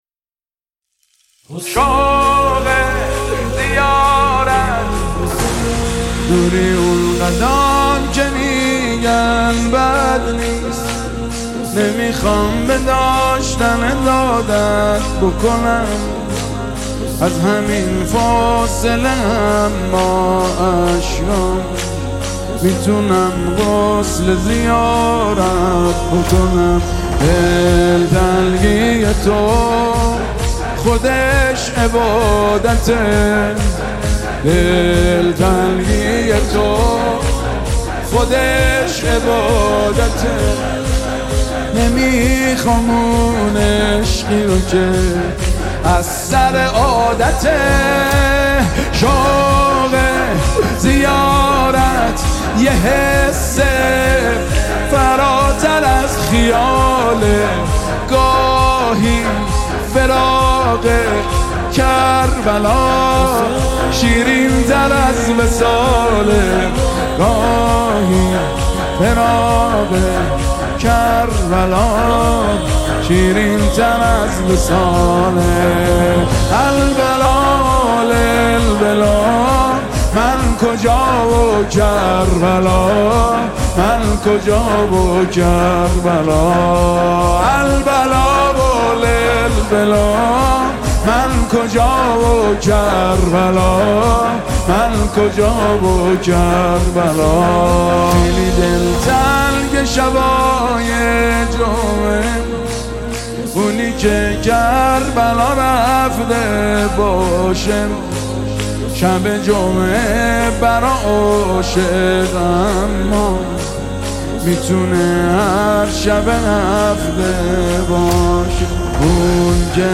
نوای دلنشین